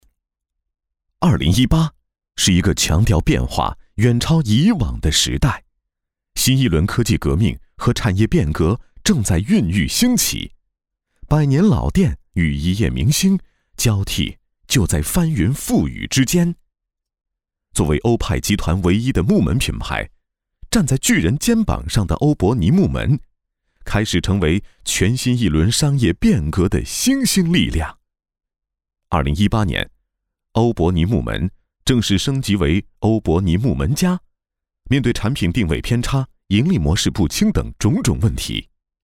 产品解说男133号
科技感 产品解说
品质高端男配。特点，自然厚重带点清亮，可根据文案把握配音风格，代表作品：农夫山泉，大国创造纪录片等。